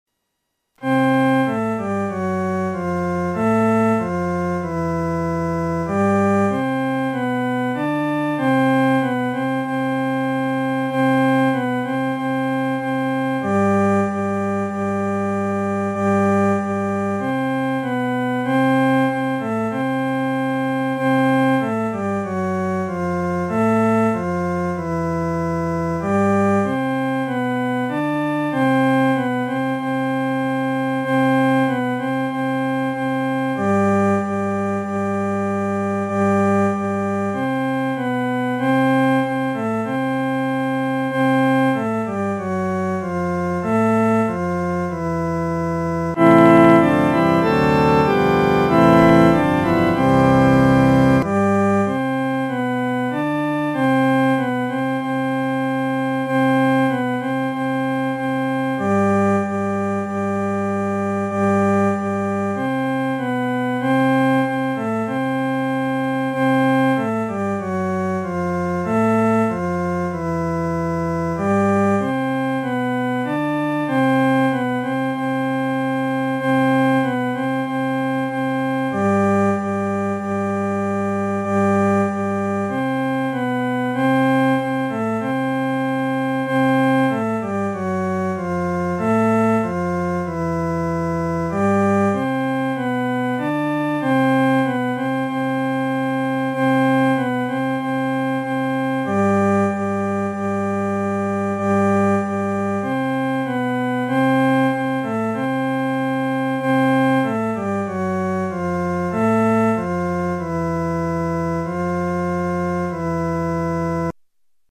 伴奏
男高
所用曲谱是在河北省一带于解放前所流行的民歌，曾被配上多种歌词。